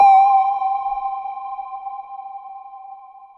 menuhit.wav